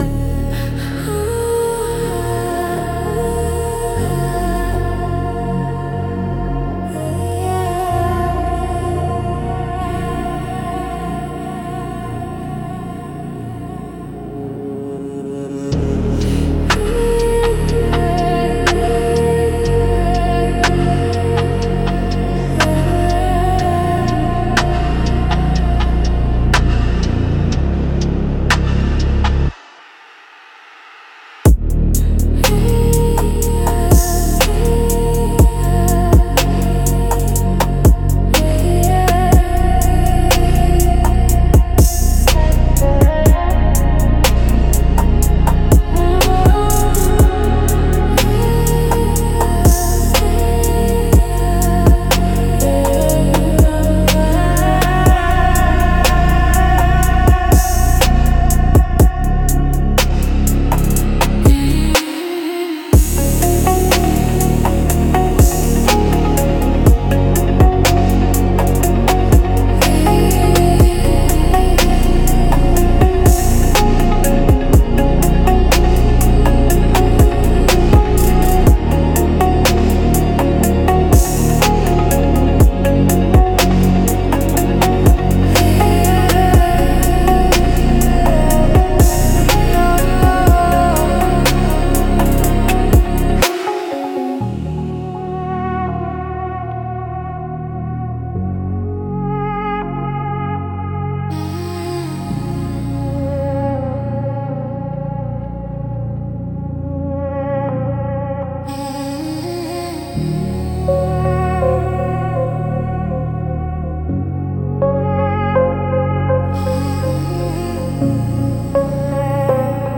Instrumental - Deep Dive 3.11